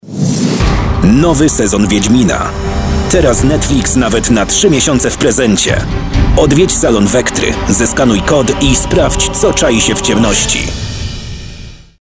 Barwny głos, malujący dźwiękami obrazy, które zaskakują i fascynują słuchacza.
Demo głosowe